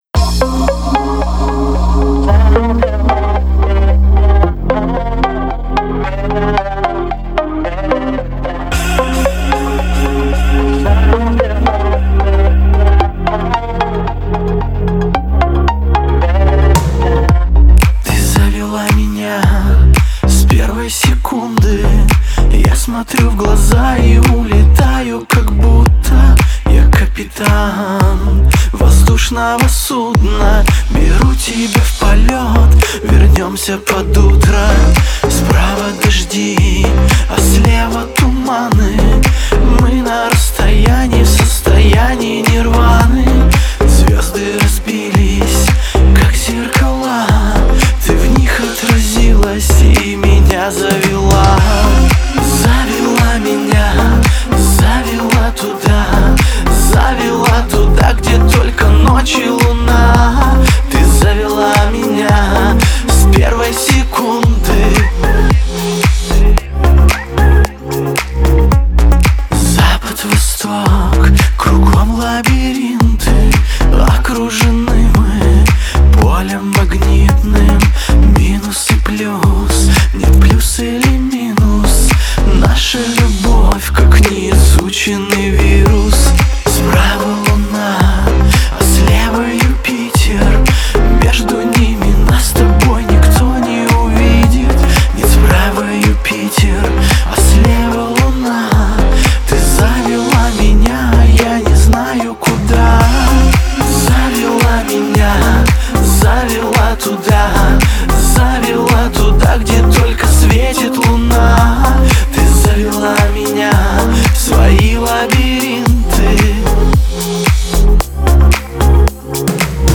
Особенностью исполнения является мощный вокал